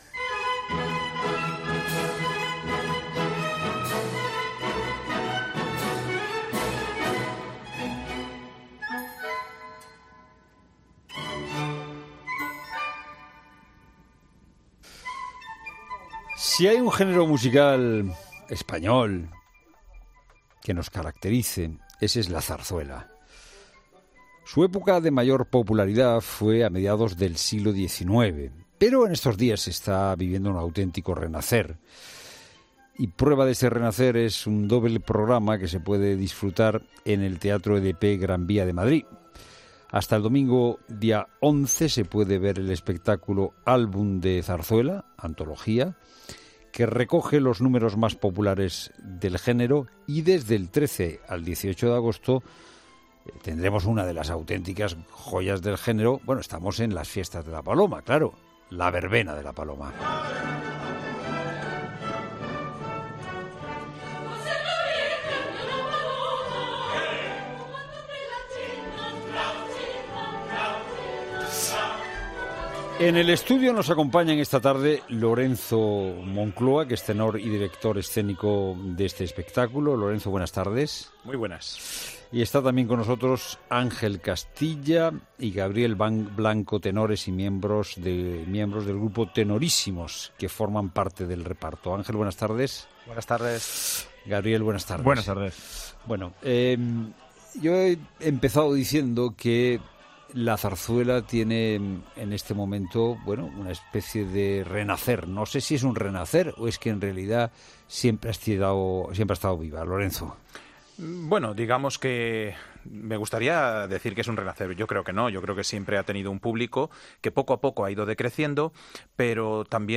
La zarzuela suena en directo en COPE y en el Teatro Gran Vía